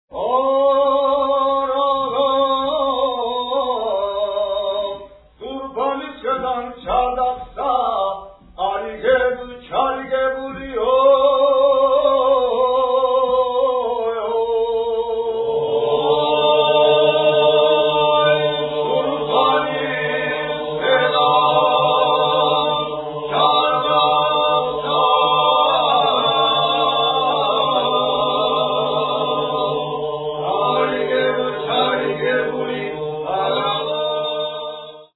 Polyphonic folk singing from various regions in Georgia.